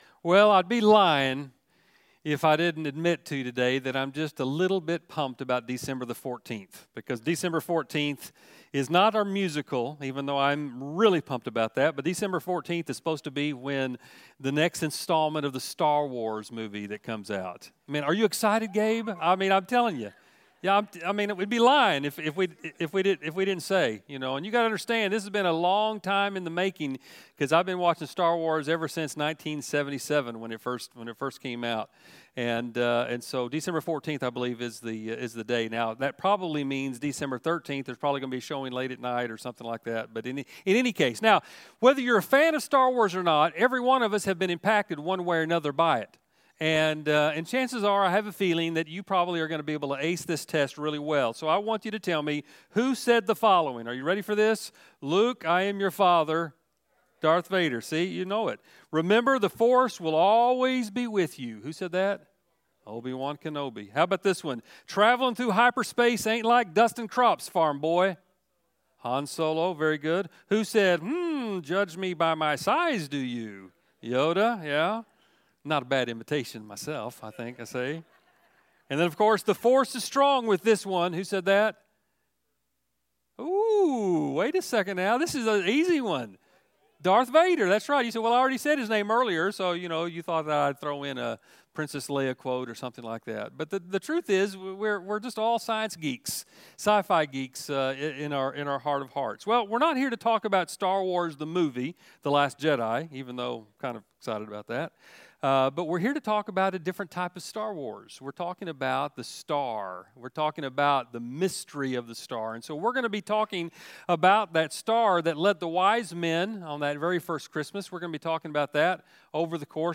A message from the series "Rewind."